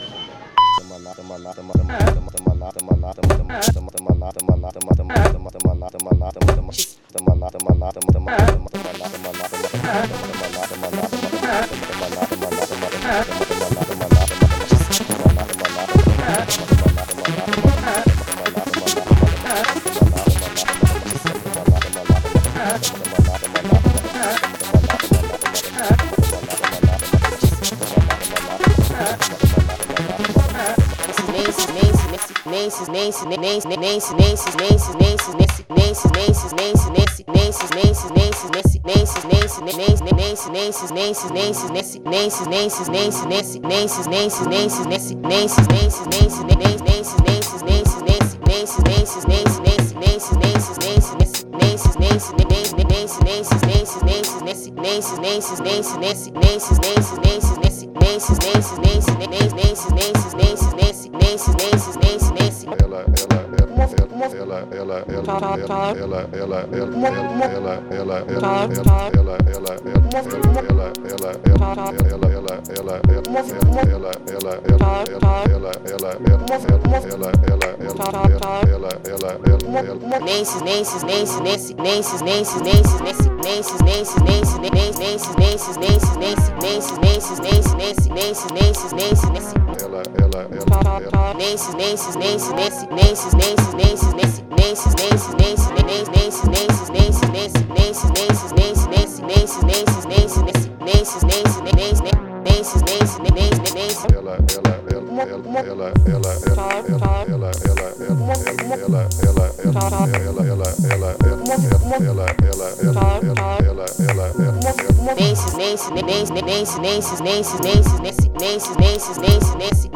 Magazine con entrevistas distendidas y frescas.